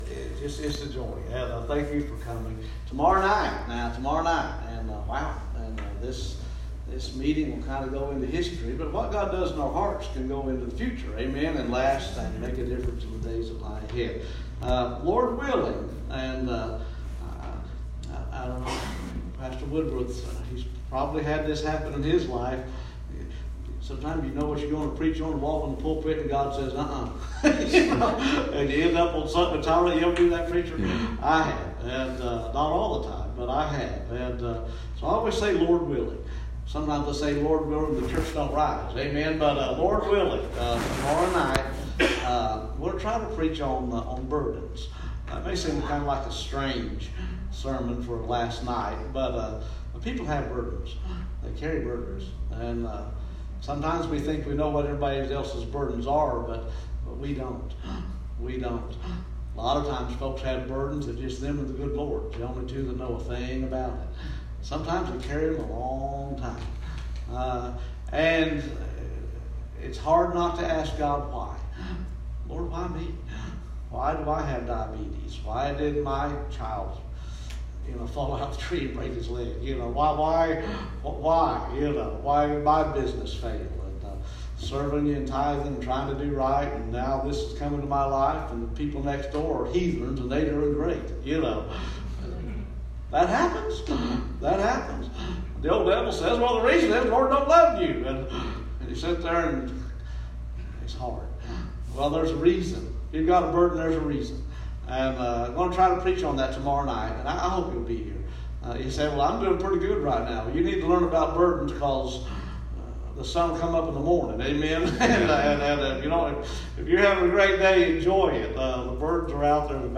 Part 4 of our 2019 Fall revival.